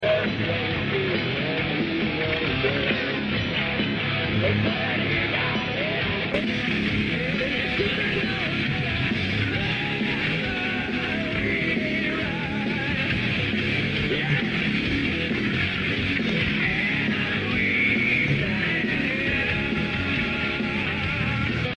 lollapalooza 1993.